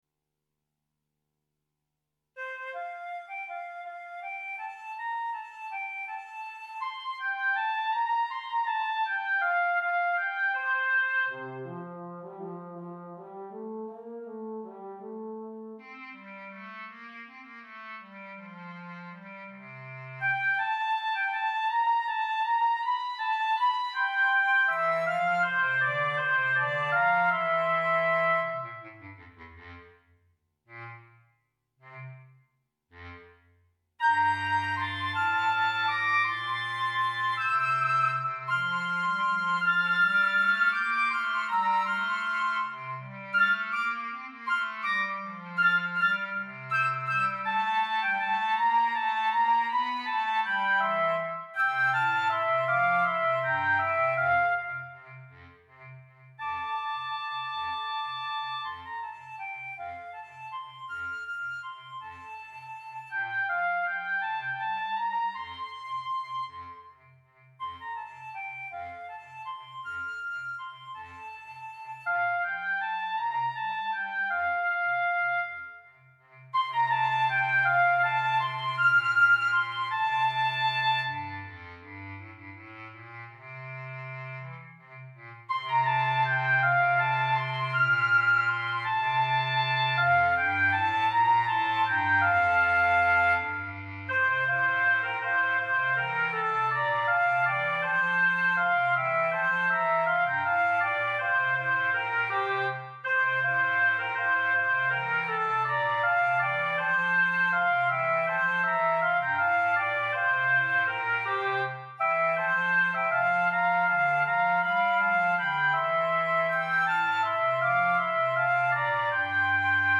minus Instrument 3